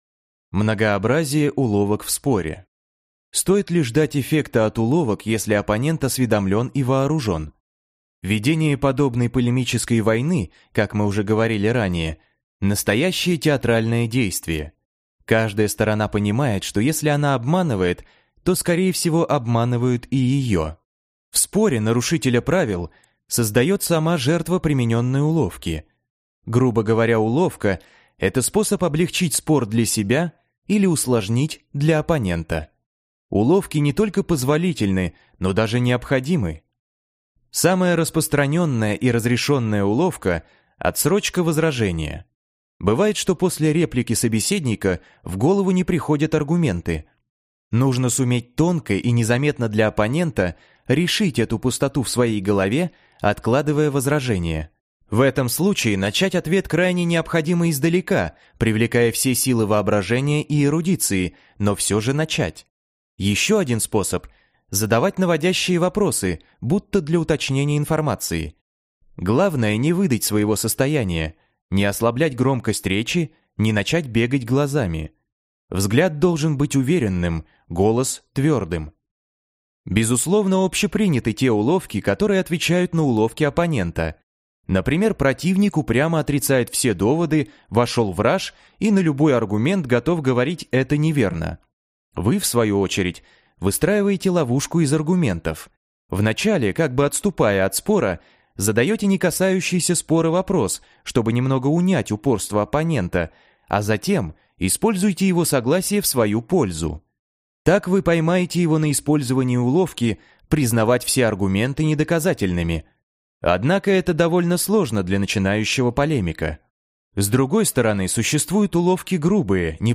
Аудиокнига Черная риторика | Библиотека аудиокниг
Прослушать и бесплатно скачать фрагмент аудиокниги